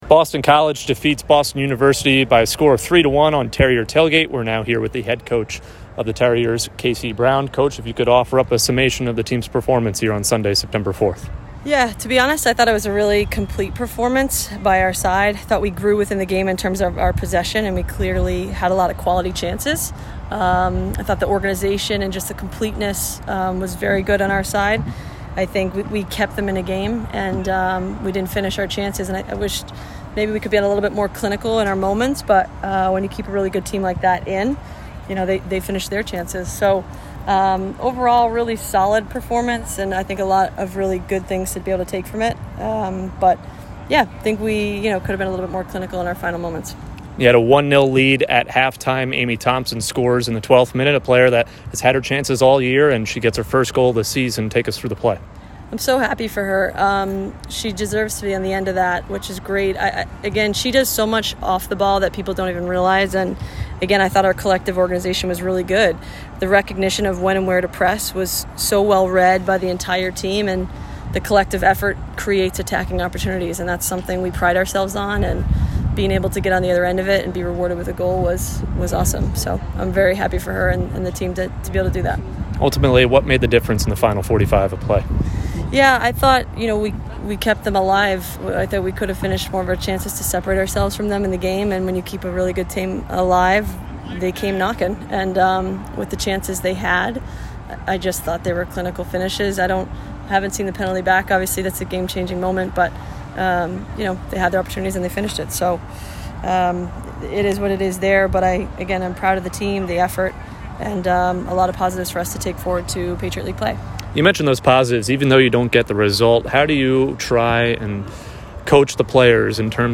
Boston College Postgame Interview